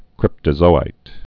(krĭptə-zōīt)